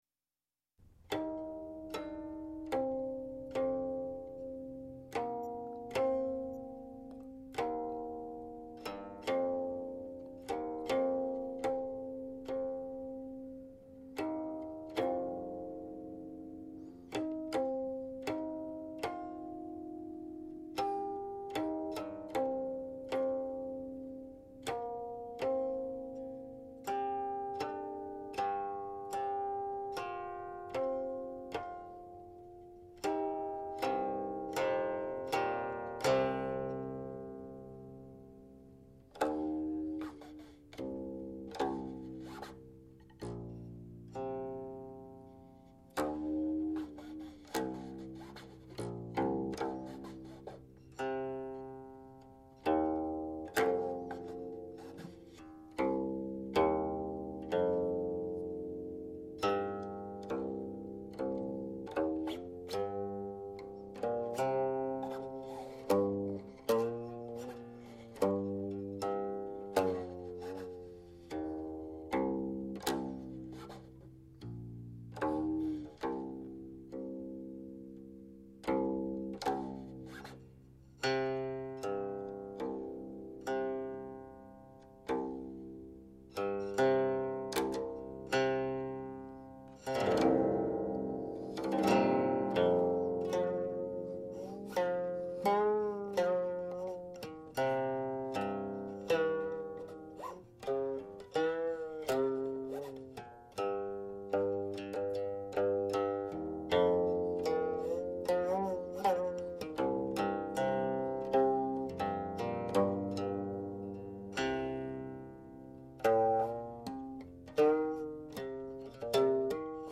1. Guqin Blues (or: Silkqin Blues 2)
Also called "Old Time Blues"; standard tuning.